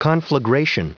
Prononciation du mot conflagration en anglais (fichier audio)
Prononciation du mot : conflagration